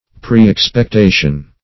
Preexpectation \Pre*["e]x`pec*ta"tion\, n. Previous expectation.